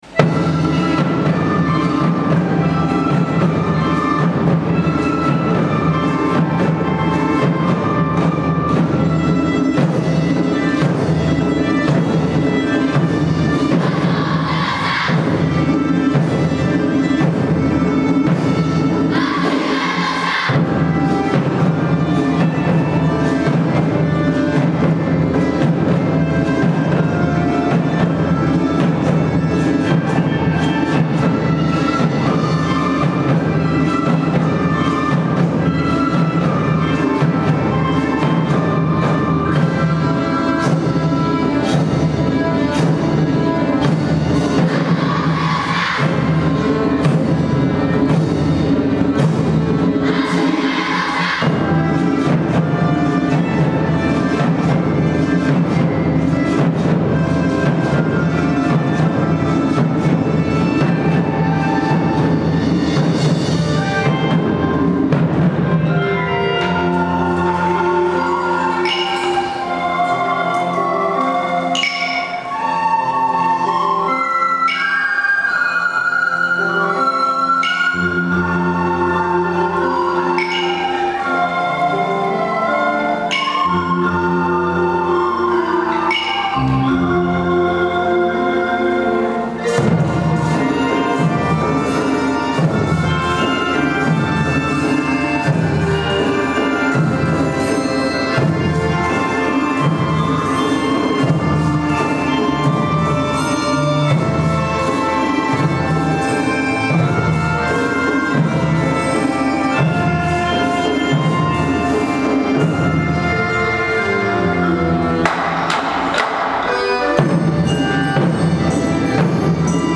日本民謡メドレー」です。まつり囃子で５年生が入場し、そこに６年生の和太鼓が入り、会場の雰囲気がさらに盛り上がりました。
大空小の創立記念コンサートでは恒例の和太鼓との合奏のスタートです♪
力強い和太鼓の音でメドレーの１曲目がスタートしました。
リコーダーの優しい音色でゆったりとしたメロディーを奏でます。